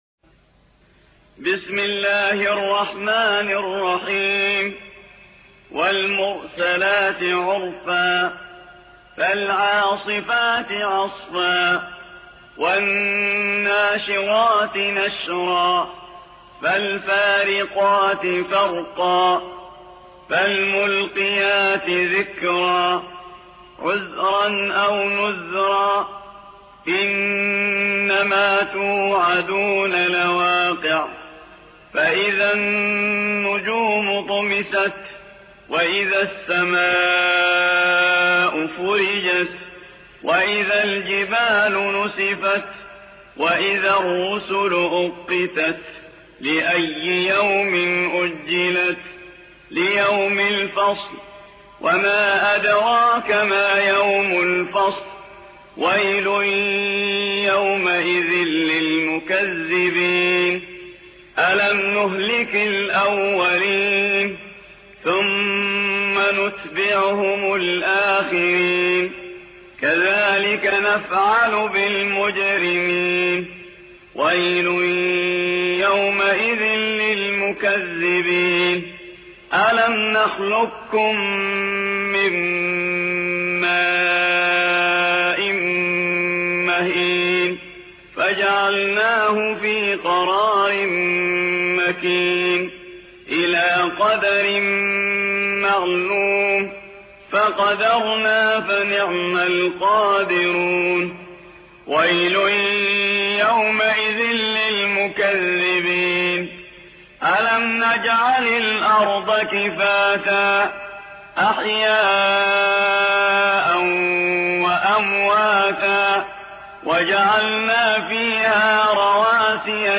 77. سورة المرسلات / القارئ